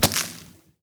Grapes Sand 12.wav